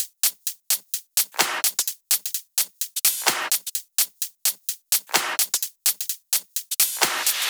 VDE 128BPM Renegade Drums 4.wav